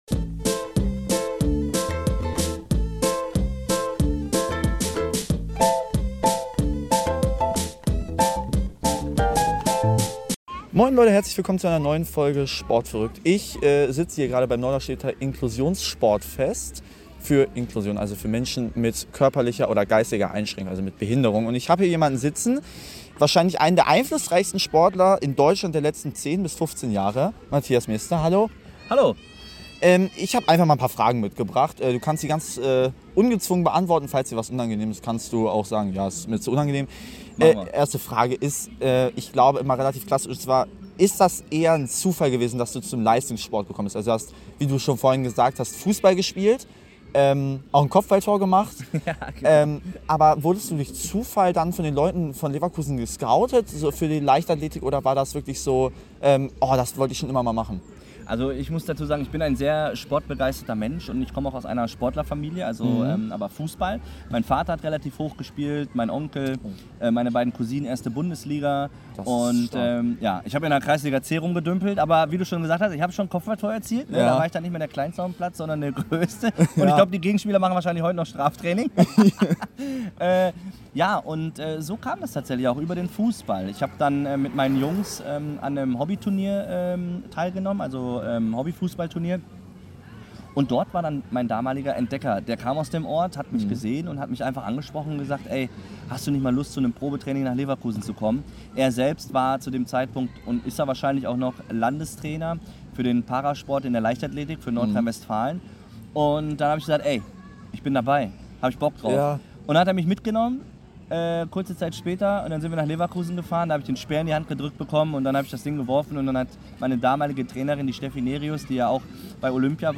Und ich konnte ihn für ein Interview gewinnen. Hier verrät der Silbermedaillengewinner von Peking 2008, wie er zur Leichtathletik gekommen ist, was sein Emotionalität Moment war und welches Motivationsmotto er hat.